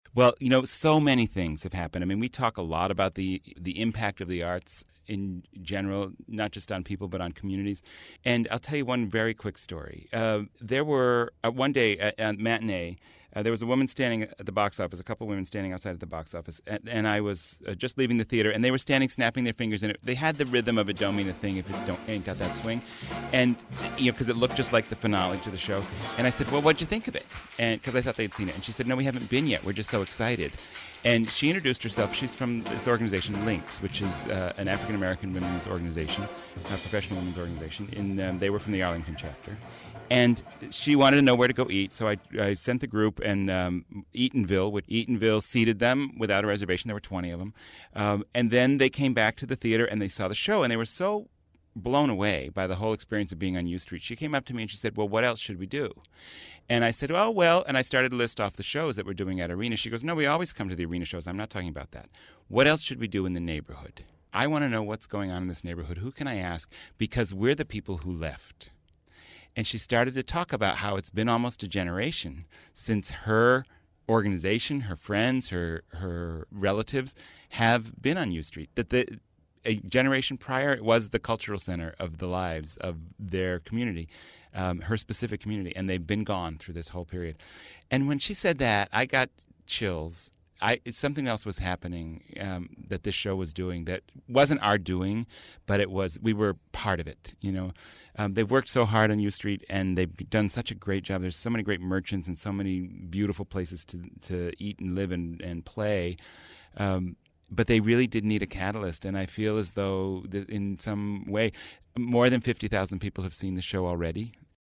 Transcript of selection from podcast on U Street, Washington, DC